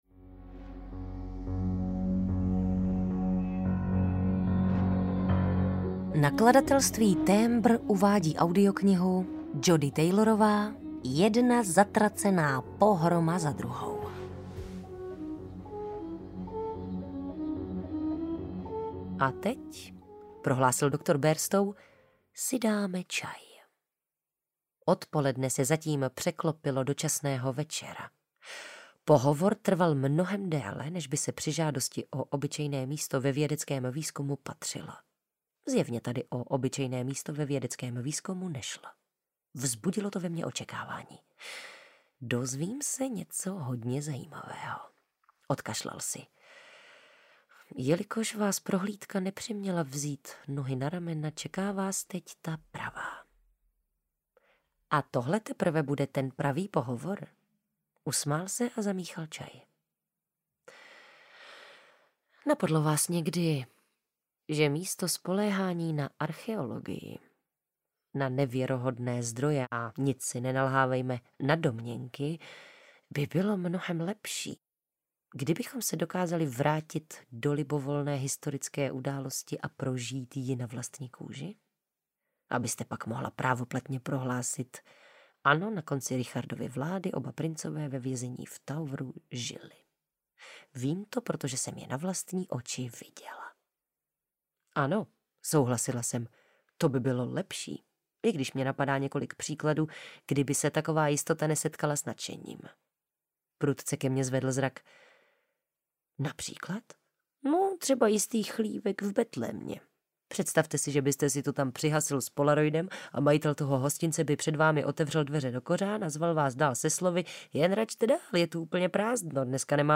Jedna zatracená pohroma za druhou audiokniha
Ukázka z knihy
• InterpretTereza Dočkalová